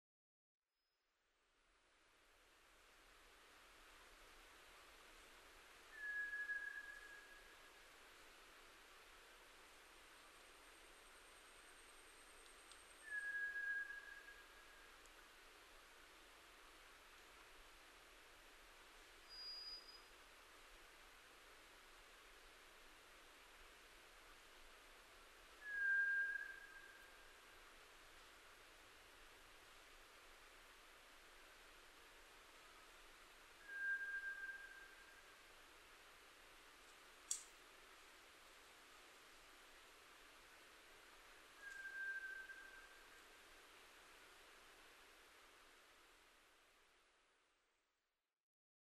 トラツグミ　Zoothera daumaツグミ科
日光市稲荷川上流　alt=1330m
Mic: Panasonic WM-61A  Binaural Souce with Dummy Head